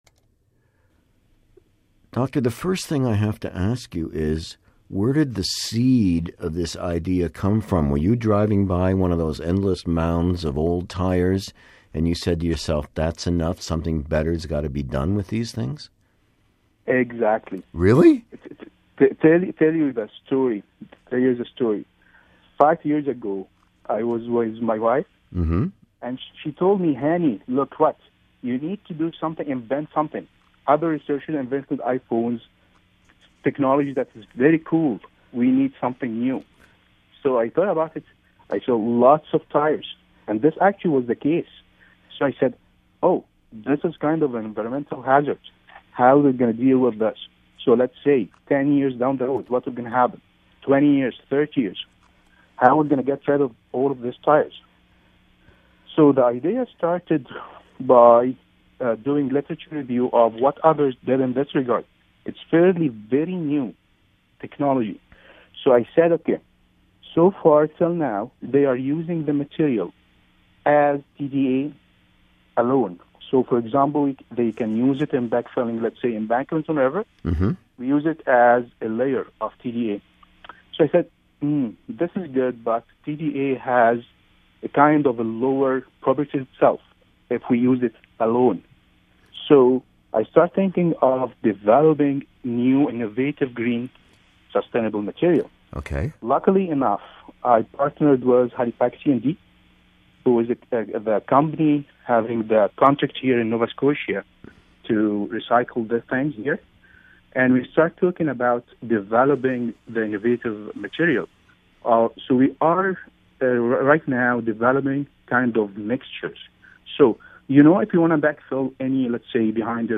He spoke by phone with RCI from his office in Halifax.